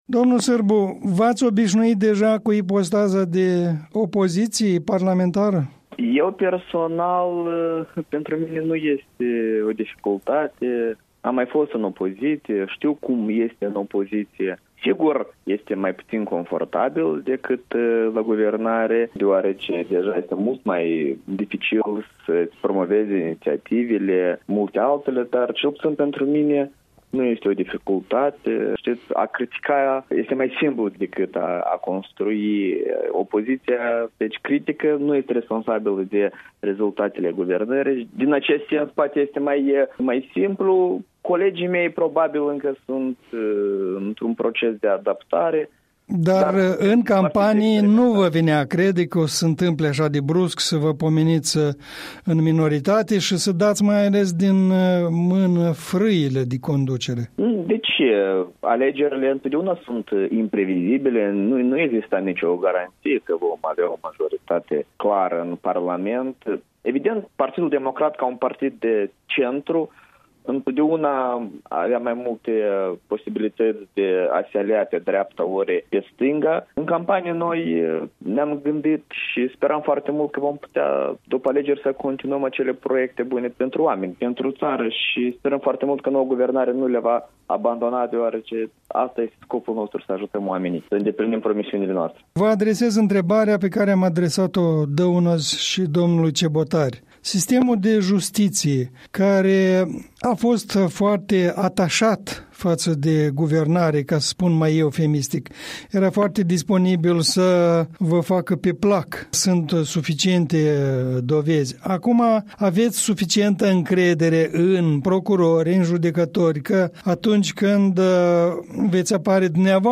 Interviu cu deputatul democrat Sergiu Sârbu